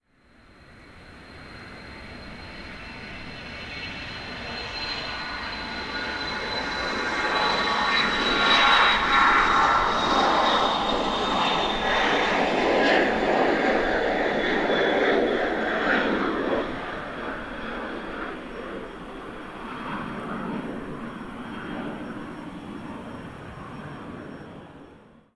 Avión Falcon despegando
avión
Sonidos: Transportes